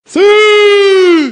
Effets Sonores